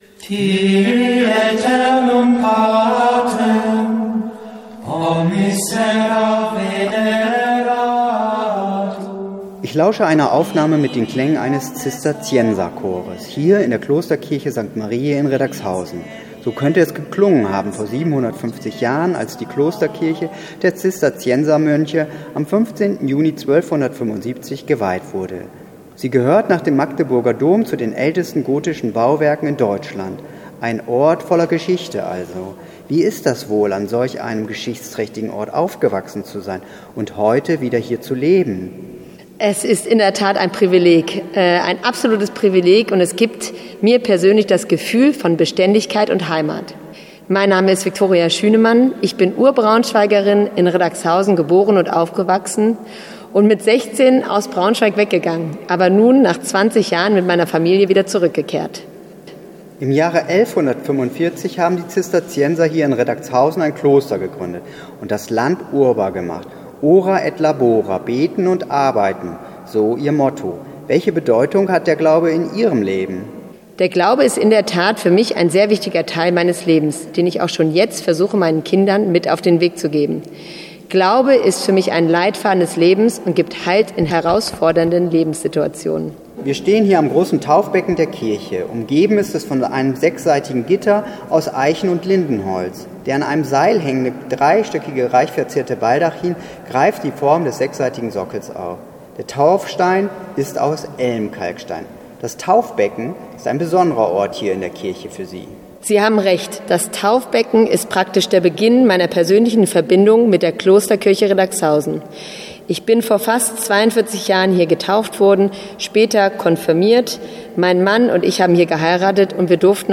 Okerwelle 104.6